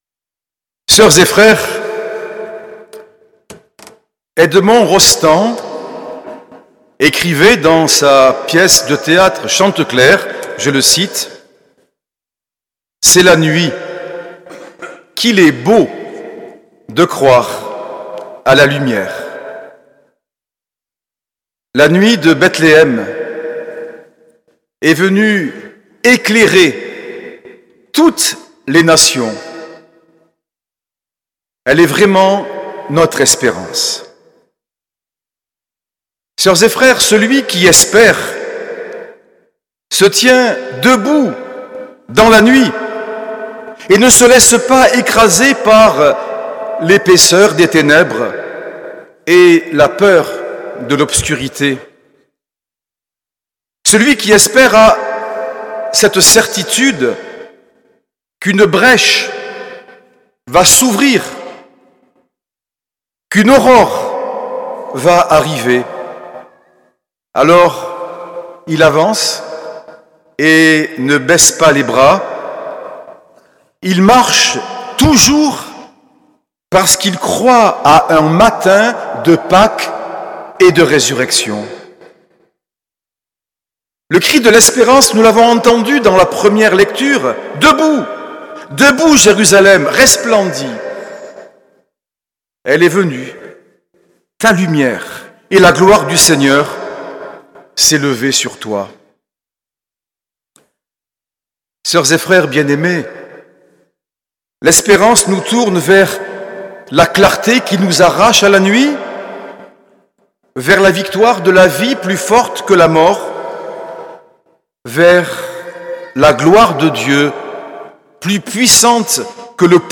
Homélie de Monseigneur Norbert TURINI, Fête de l’Épiphanie du Seigneur, le 5 janvier 2025
L’enregistrement retransmet l’homélie de Monseigneur Norbert TURINI, puis ses recommandations pour l’année sainte de l’Espérance dans notre diocèse.